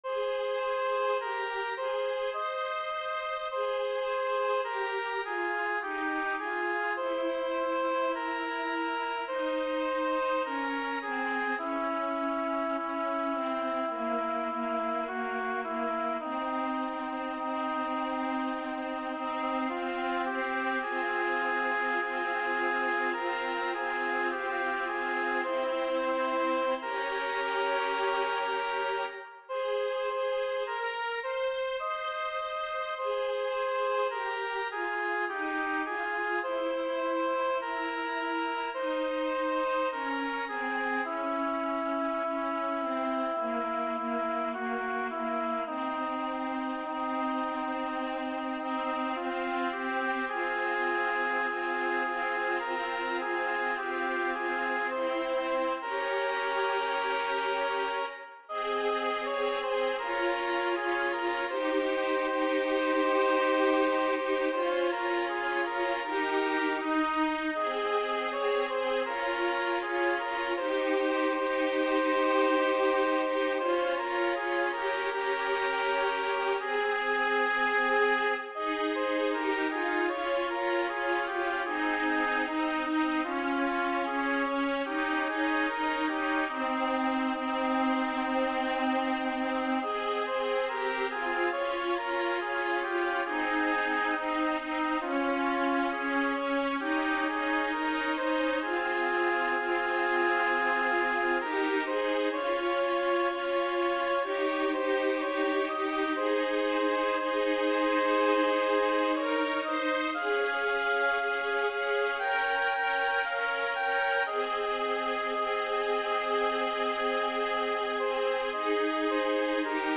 Number of voices: 3vv Voicing: SAA Genre: Sacred
Language: Italian Instruments: A cappella